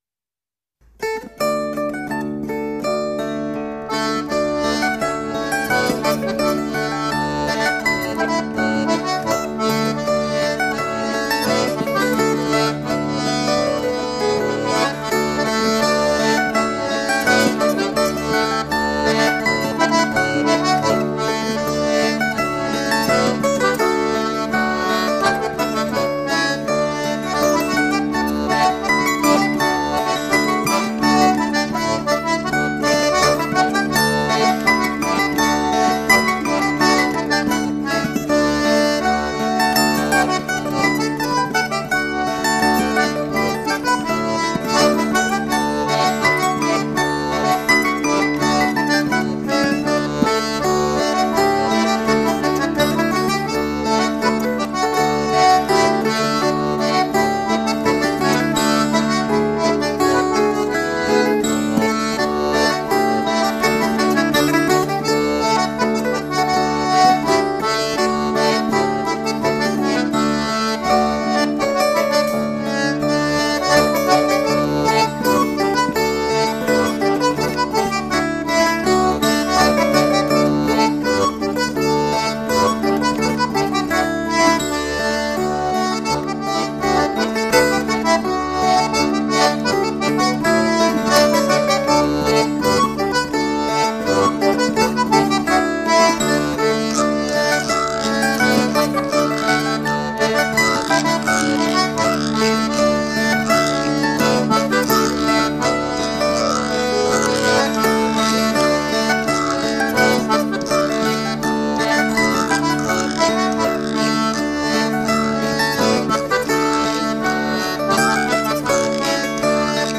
scottisch ou allemande à quatre 3'19